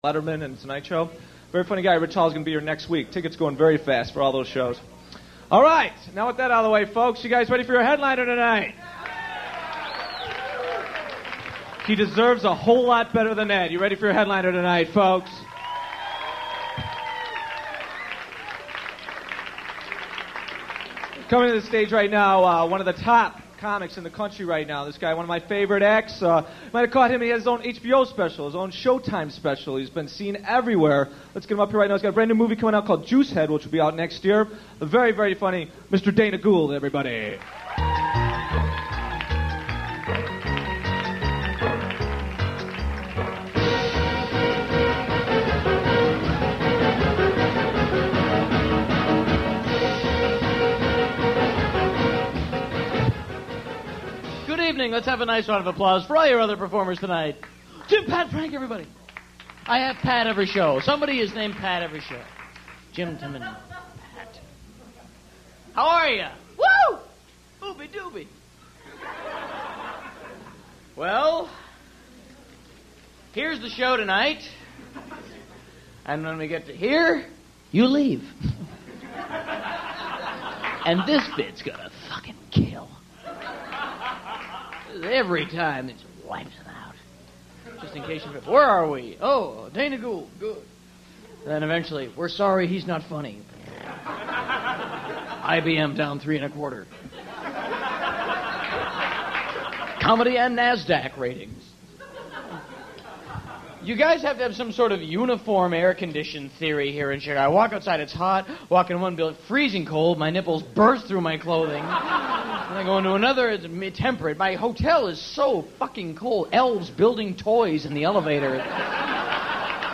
Dana Gould - Chicago Funny Farm 1993.mp3